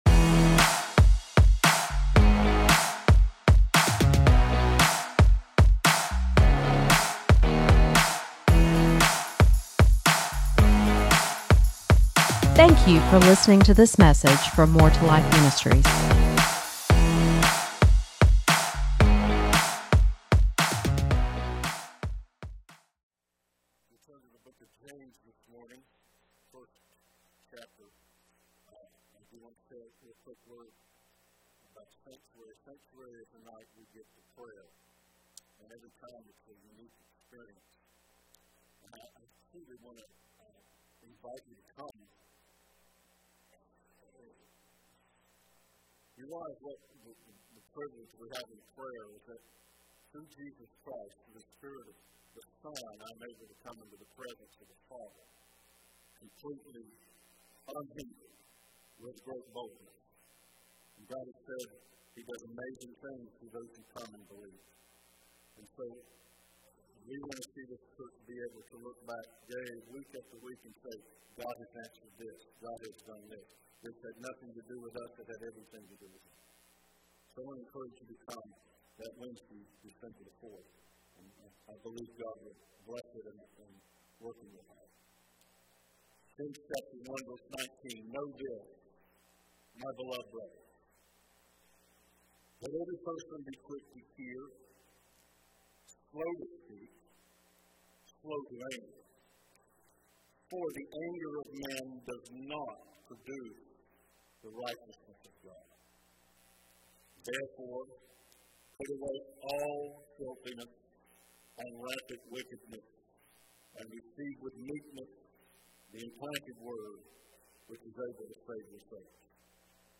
Sermons | More 2 Life Ministries